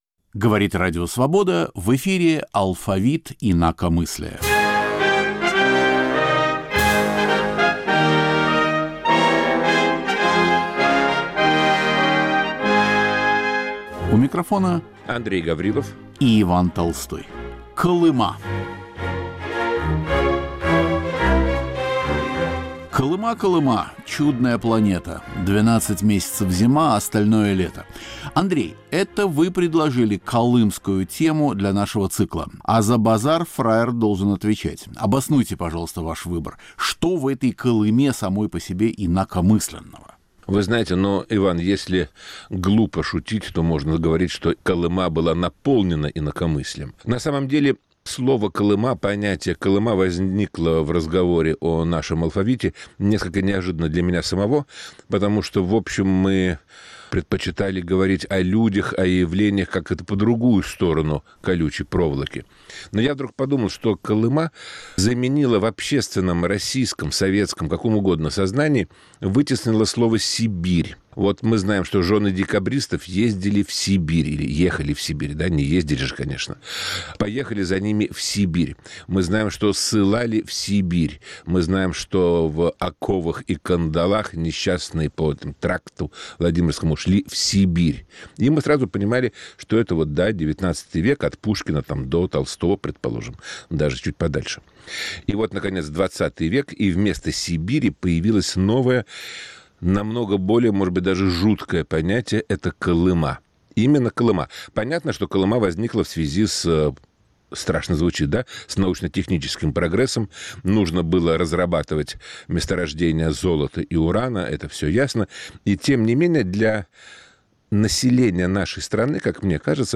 Рассказы, стихи, воспоминания о годах, проведенных в ГУЛаге. Звучат песни о Колыме - трагические, драматические и даже пересмешнические.